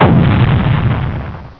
embedding, you can hear the sound of the
torpedo hit, by clycking on the image)